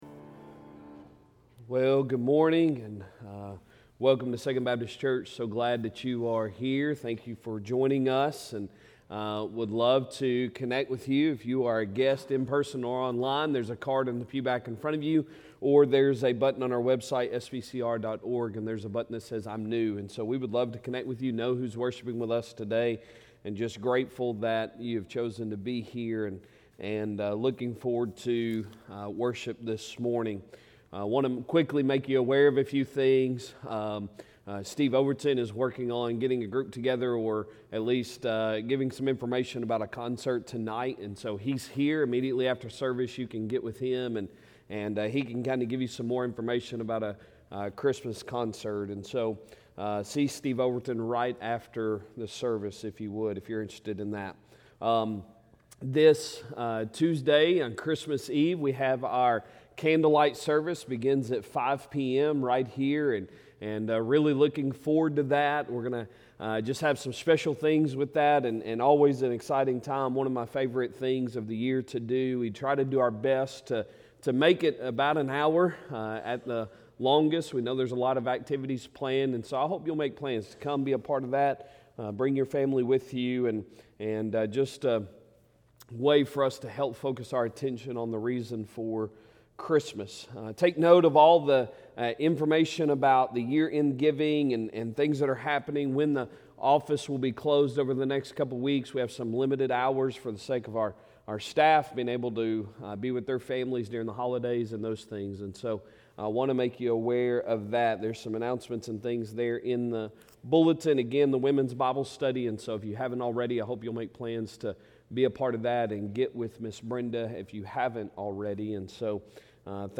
Sunday Morning Sermon December 22, 2024